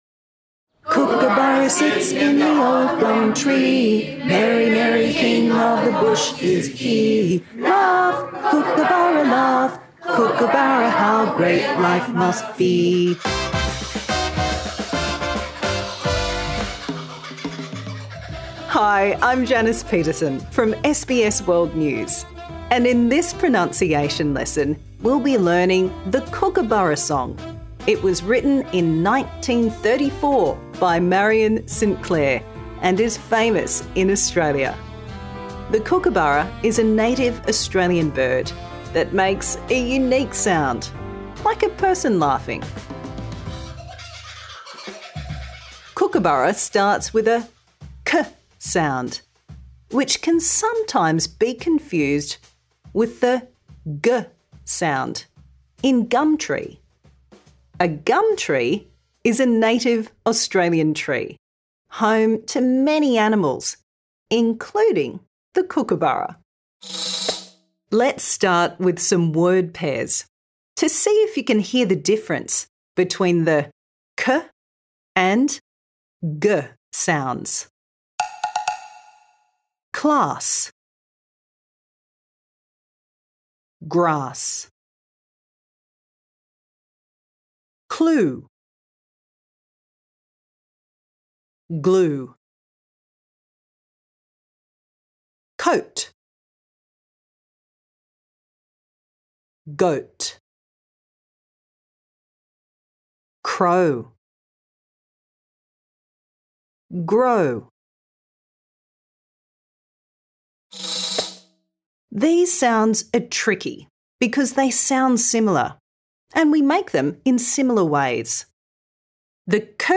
Improve your pronunciation | Season 2